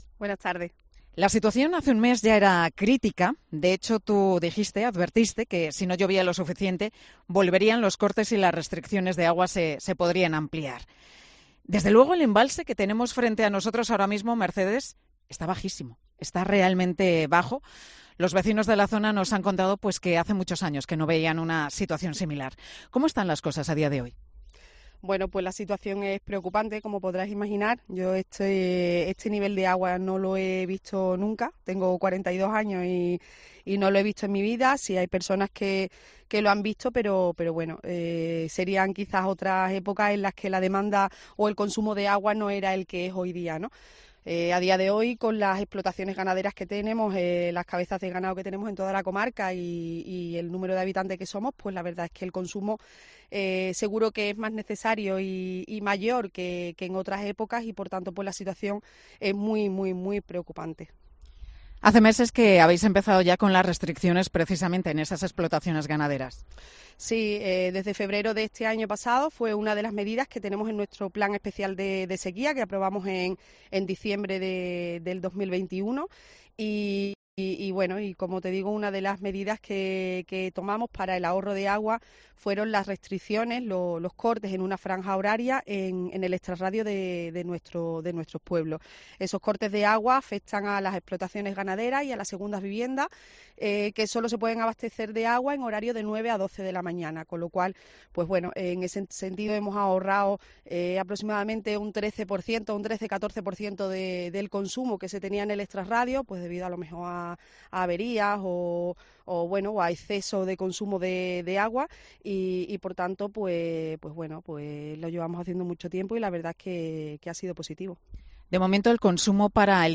Este miércoles, en el que toda la Cadena COPE está pendiente de la situación de extrema sequía que afecta a toda España, con los comunicadores distribuidos de norte a sur, en los sitios en los que esa falta de agua es más acuciante, Mediodía COPE se ha trasladado hasta Badajoz.